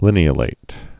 (lĭnē-ə-lāt)